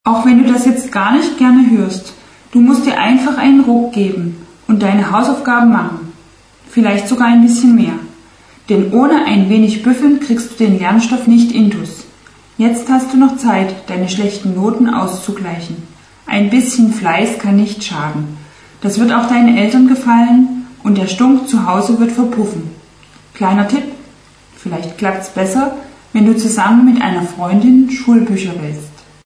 Hört euch jetzt die Antwort der Psychologin an.